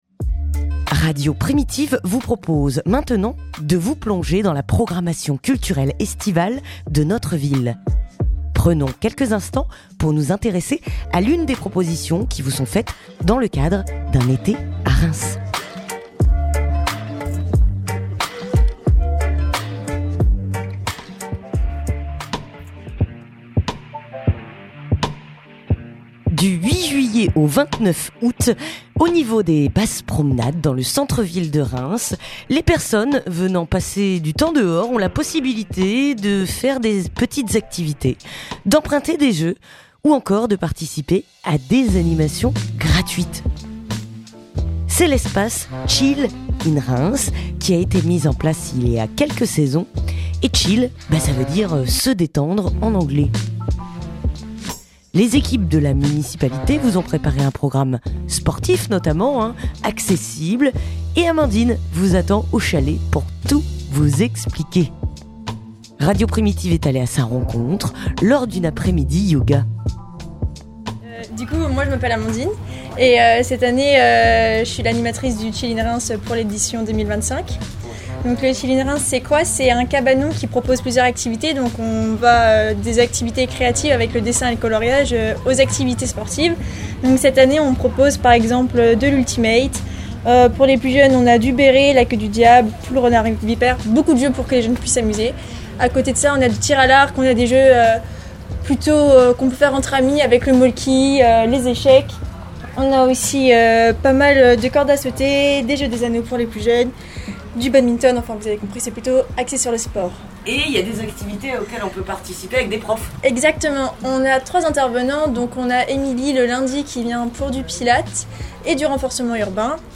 Reportage aux promenades